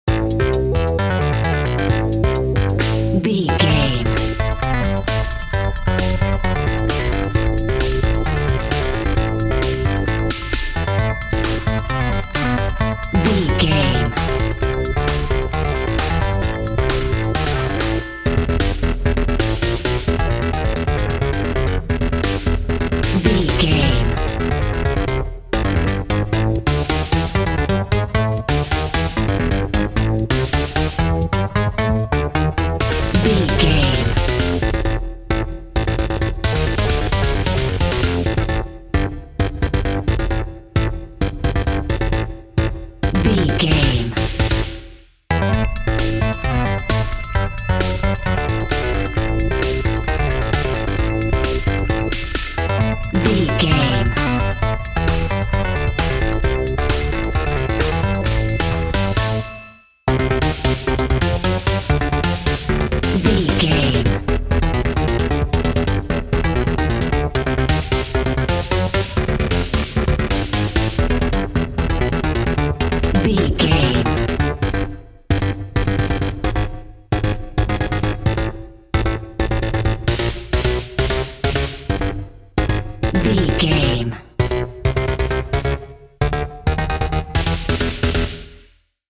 Fast paced
In-crescendo
Aeolian/Minor
Fast
chaotic
industrial
synthesiser
percussion
drum machine